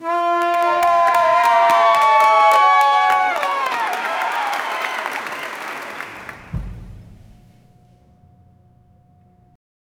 Each stroke should be squishy, and slightly wet, with a gooey texture. 0:05 Page flip, faint stadium cheer, paintbrush stroke, orchestral lift with strings and drums, powerful horns, taiko beats, final crowd cheer, ending in silence. 0:10
page-flip-faint-stadium-c-p2bv3qdl.wav